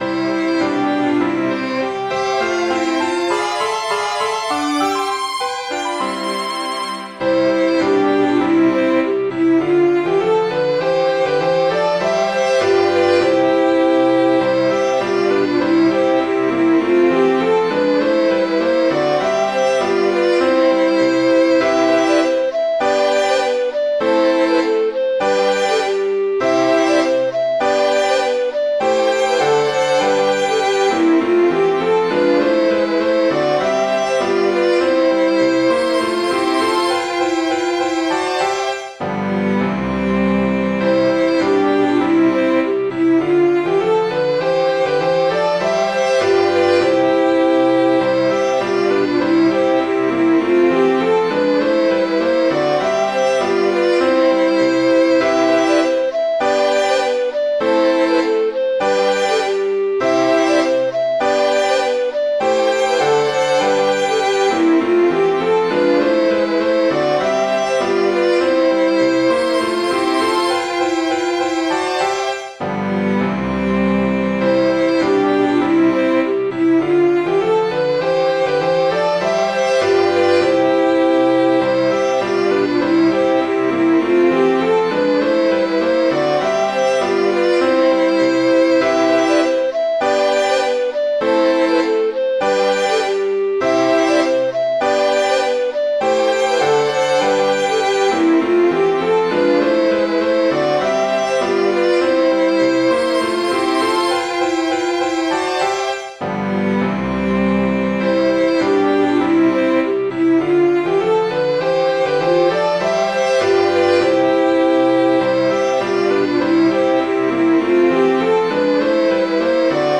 riselark.mid.ogg